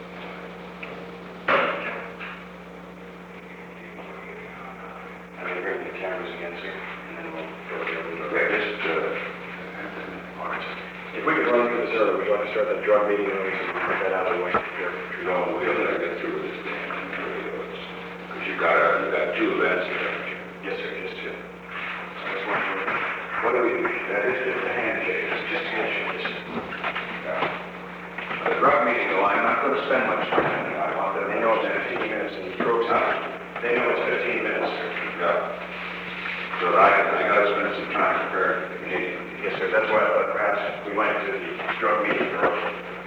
Recording Device: Oval Office
The Oval Office taping system captured this recording, which is known as Conversation 630-011 of the White House Tapes.